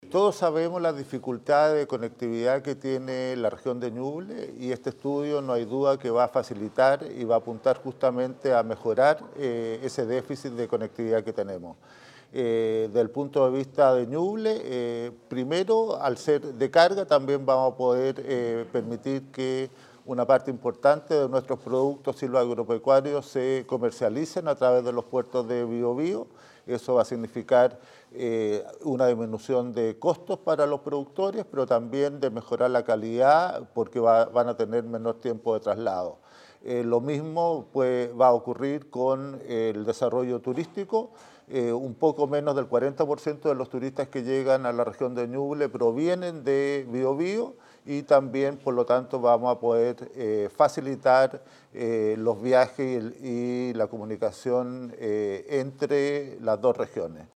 En un punto de prensa, el delegado presidencial regional de Ñuble, Rodrigo García Hurtado, explicó que “al considerar tren de carga, también va a permitir que una parte importante de nuestros productos silvoagropecuarios se comercialicen a través de los puertos de Biobío y lo mismo va a ocurrir con el desarrollo turístico”.